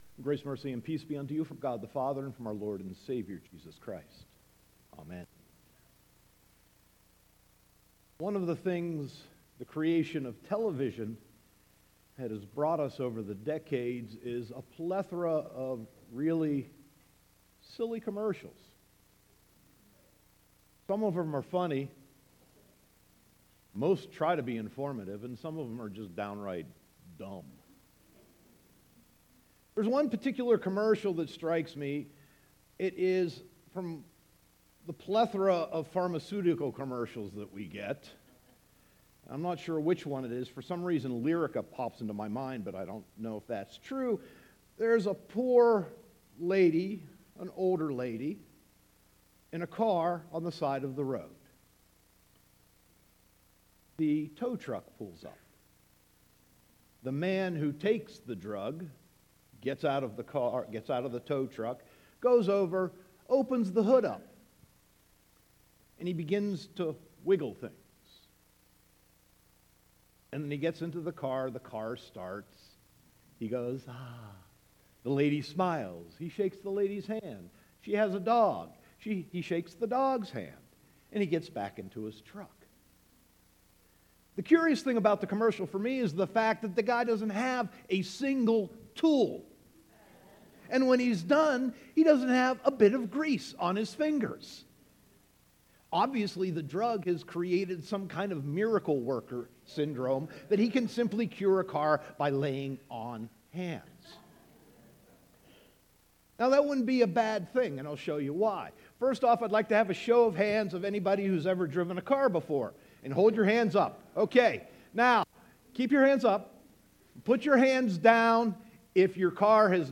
Sermon 3.4.2018